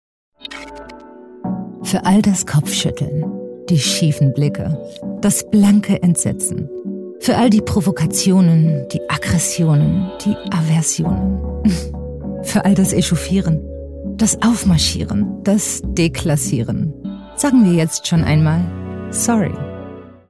Meine Stimme ist warm, klar und vielseitig einsetzbar – ob für Werbung, Imagefilme, E‑Learnings, Audioguides oder Dokumentationen.
Kund:innen schätzen meinen natürlichen, empathischen Ton, die präzise Aussprache und eine zuverlässige, unkomplizierte Zusammenarbeit.
Broadcast‑ready Aufnahmen entstehen in meinem eigenen Studio in Berlin.
Sprechprobe: Werbung (Muttersprache):